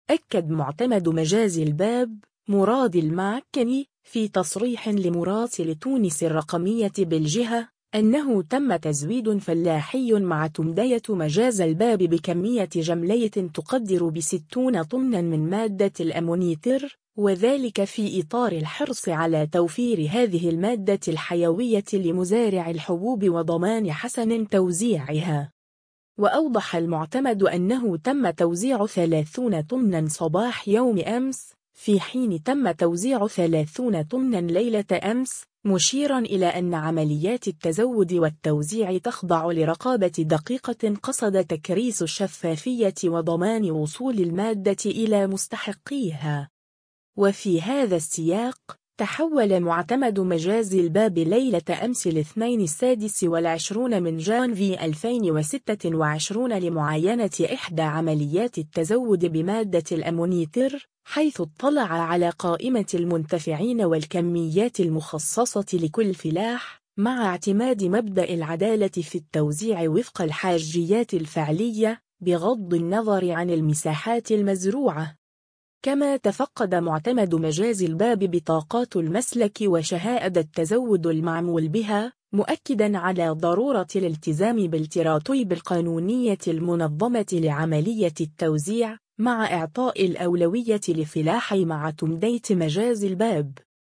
أكد معتمد مجاز الباب، مراد الماكني، في تصريح لمراسل “تونس الرقمية” بالجهة، أنه تم تزويد فلاحي معتمدية مجاز الباب بكمية جملية تقدّر بـ60 طناً من مادة الأمونيتر، وذلك في إطار الحرص على توفير هذه المادة الحيوية لمزارعي الحبوب وضمان حسن توزيعها.